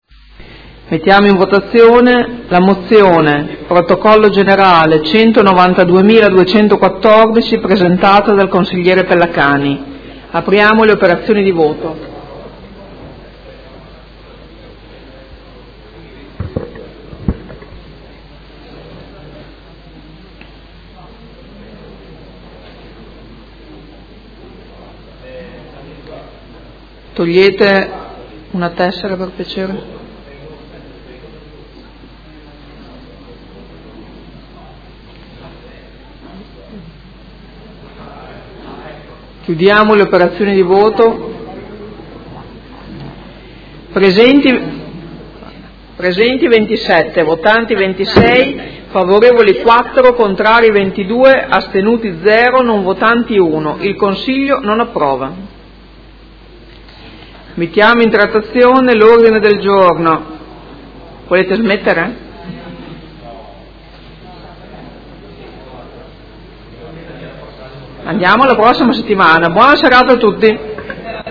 Seduta del 19/04/2018. Mette ai voti Mozione presentata dal Consigliere Pellacani (Energie per l’Italia) avente per oggetto: Condanna delle manifestazioni violente che hanno invaso e messo a soqquadro il centro di Modena il 15 dicembre scorso ed impegno ad evitare in futuro il ripetersi di analoghe situazioni.